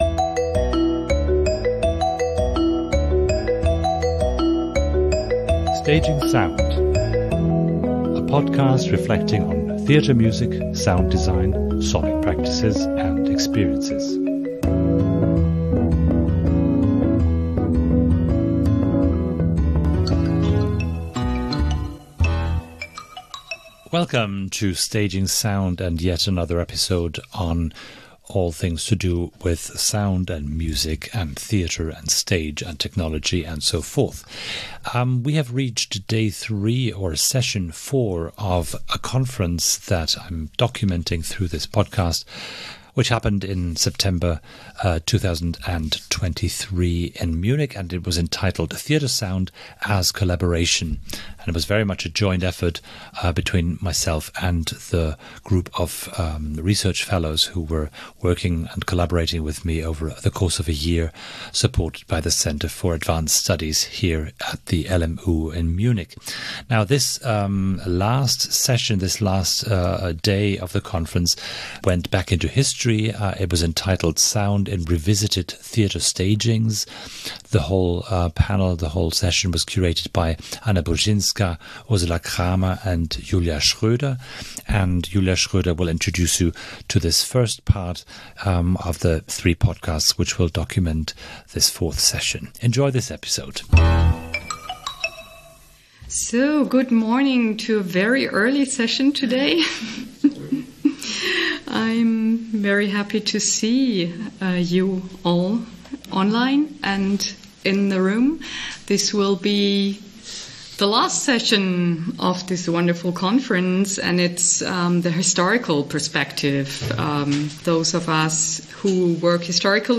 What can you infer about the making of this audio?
Reflecting theatre music and sound design - Conference documentation: Theatre Sound as Collaboration. Part 4.1